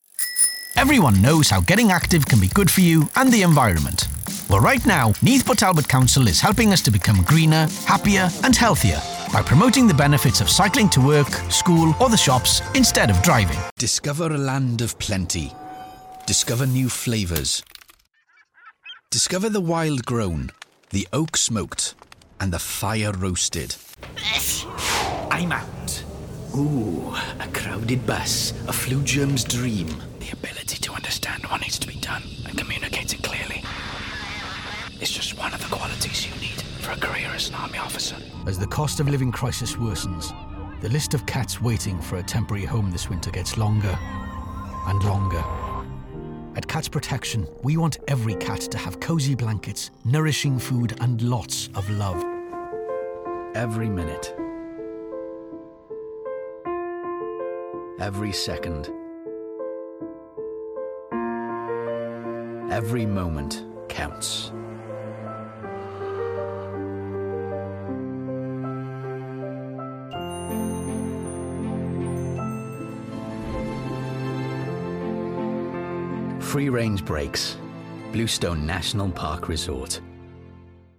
Southern Welsh, Northern Welsh, RP, Liverpool,
Middle Aged
COMMERCIAL REEL 24.mp3